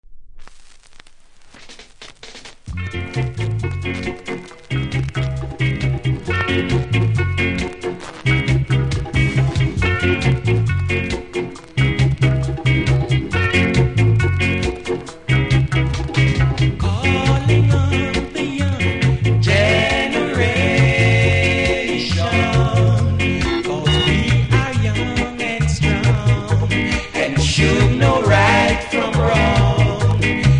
両面後半キズとプレス起因のノイズあります。